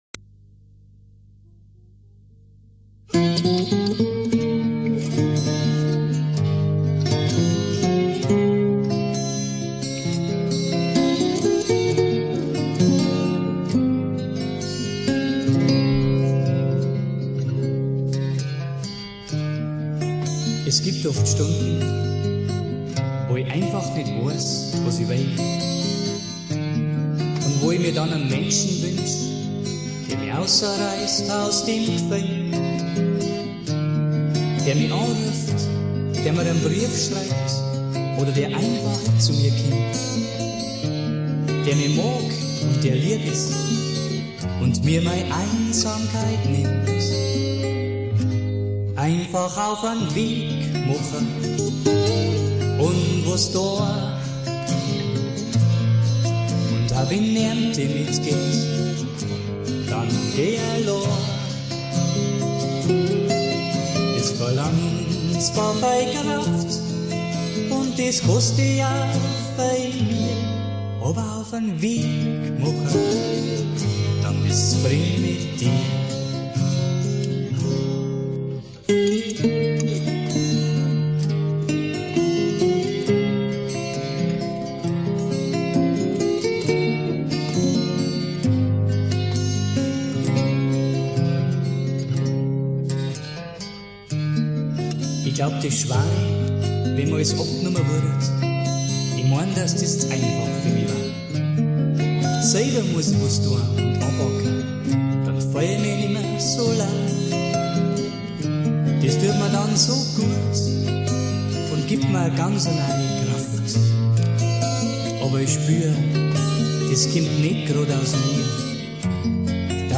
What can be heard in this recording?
Gesang, Gitarre